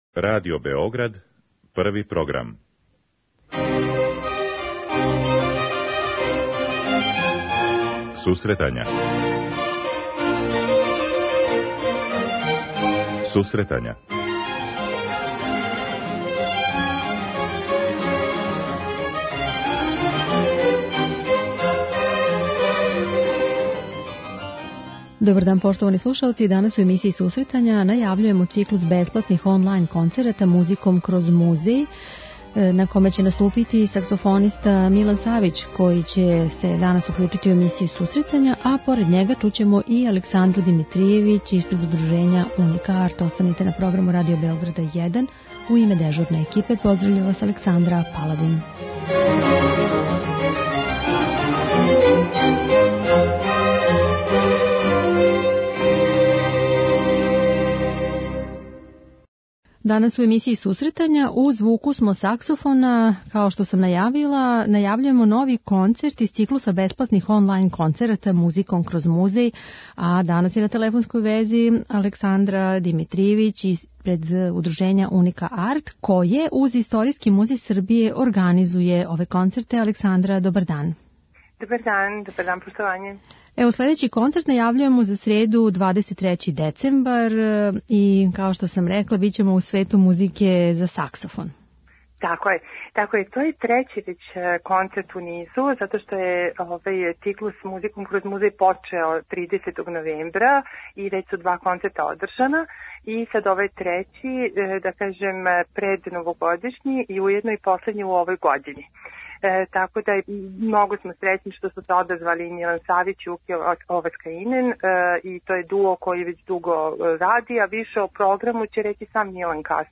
Емисија за оне који воле уметничку музику.
Аудио подкаст